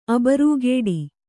♪ abarūgēḍi